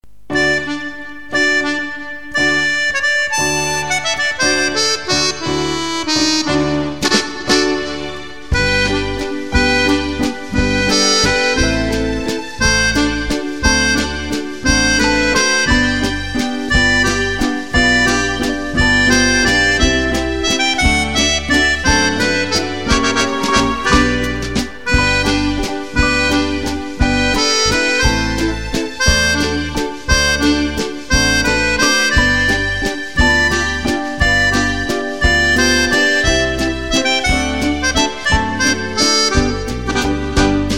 Valse Viennoise